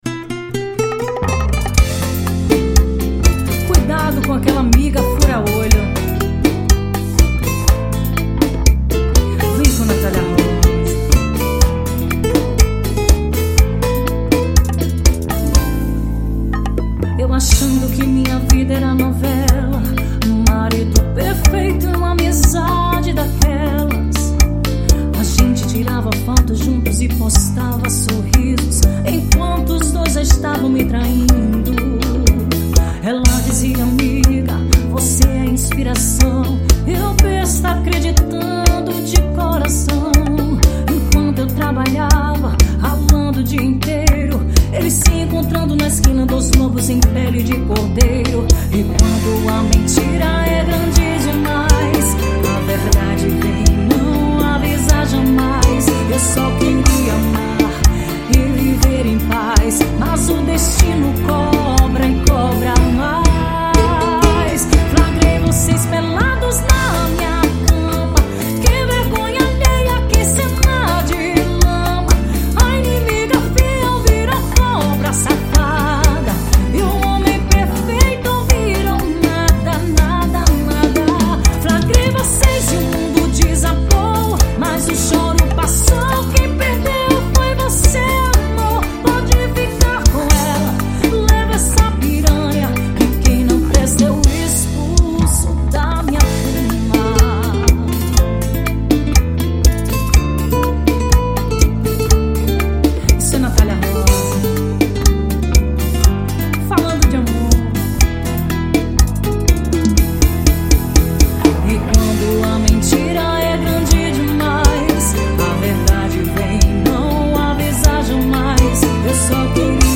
EstiloArrocha